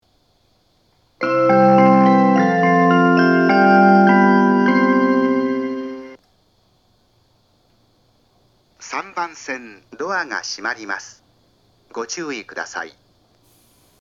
発車メロディーは南武線オリジナルメロディーです。
発車メロディー
余韻切りです。鳴りやすさは車掌次第です。
ユニペックス小丸型（2・3番線）